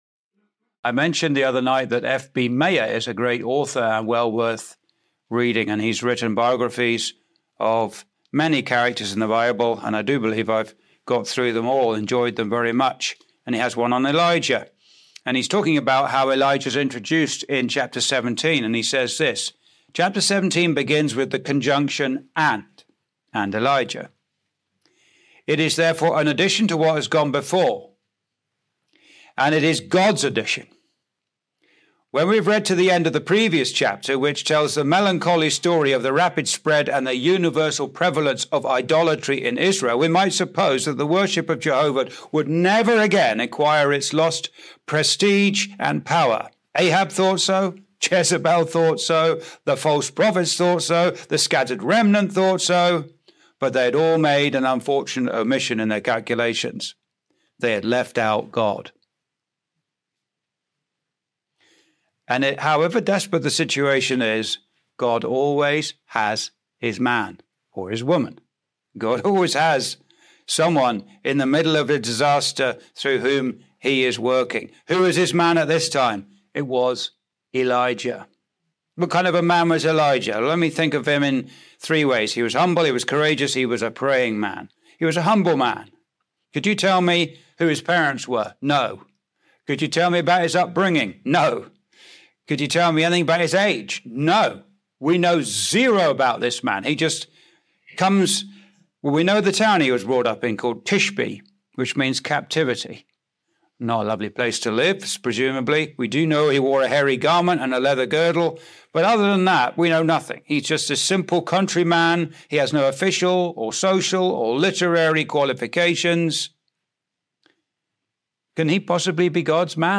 (Recorded in The Malden Road Gospel Hall, Windsor, ON, Canada on 13th Jan 2026)